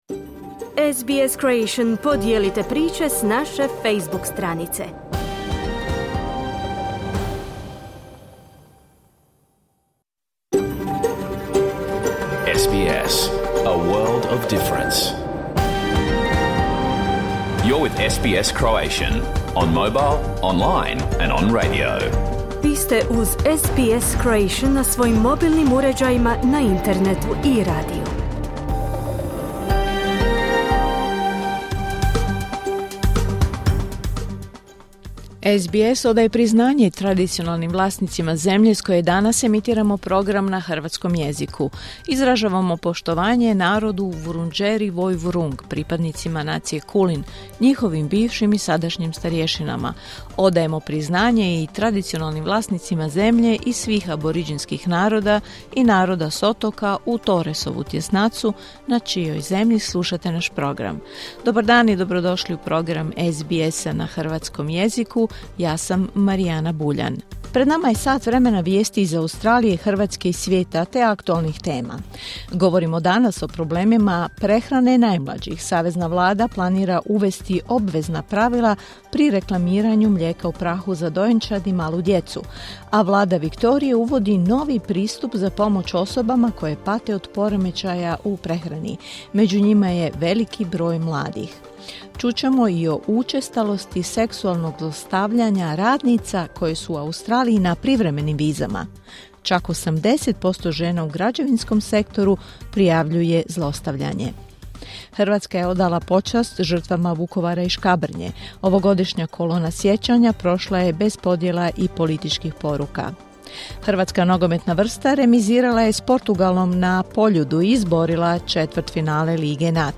Vijesti i aktualnosti iz Australije, Hrvatske i ostatka svijeta. Emitirano uživo na radiju SBS1, u 11 sati po istočnoaustralskom vremenu.